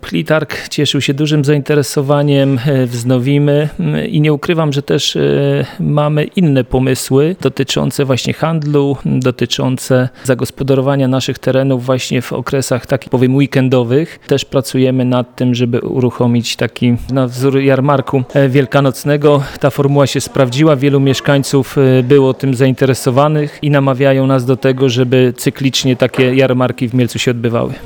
Mamy tez pomysły na podobne tego typu działania w przyszłości , mówi prezydent Mielca Jacek Wiśniewski.